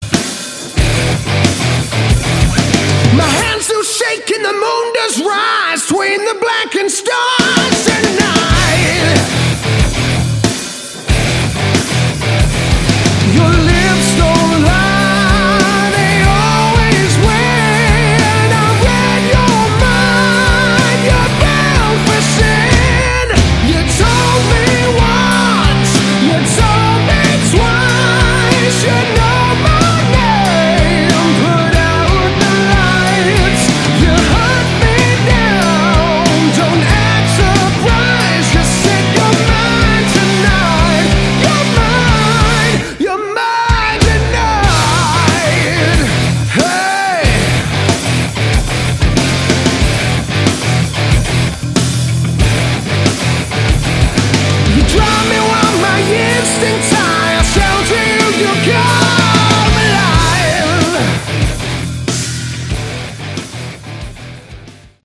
Category: Hard Rock
vocals
lead guitars
bass guitar
drums